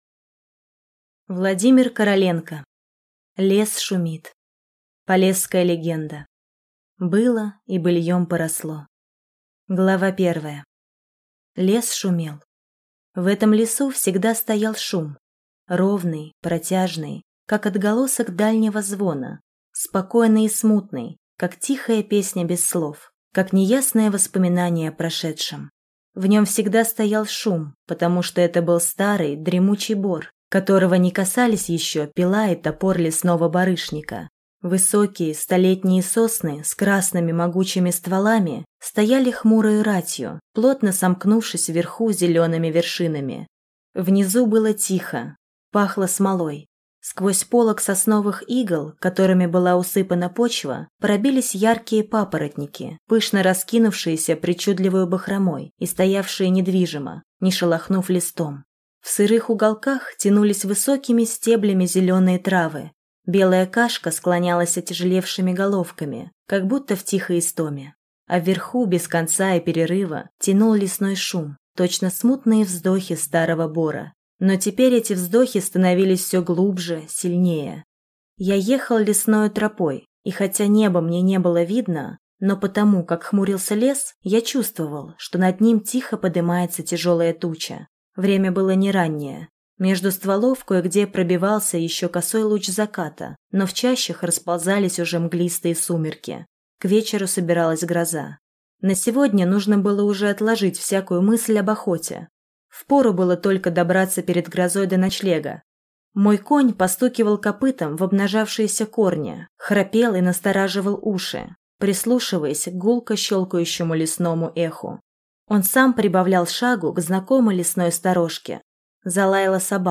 Аудиокнига Лес шумит | Библиотека аудиокниг